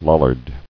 [Lol·lard]